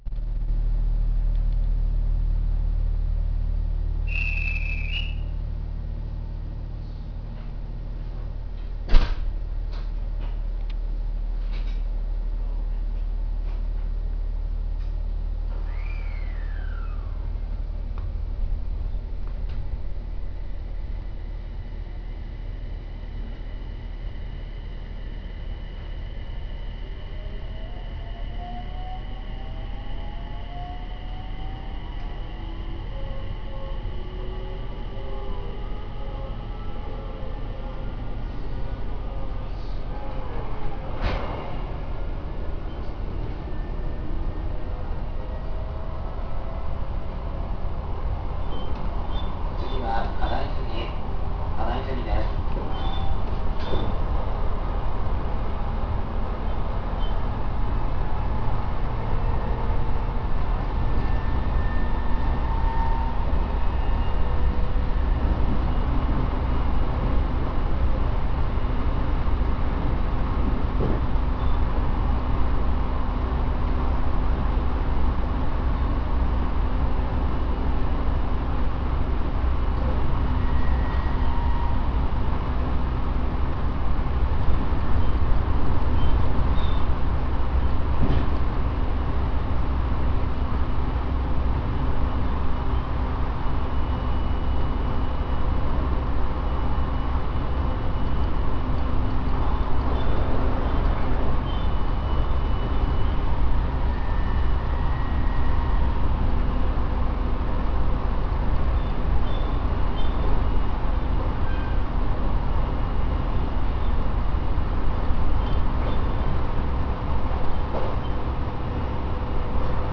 ・E721系走行音
【東北本線】清水原→花泉（3分11秒：1.01MB）
半自動扱い故いきなり出発するところから始まる音声も含まれます。聞き慣れない音ですが一応三菱製との事。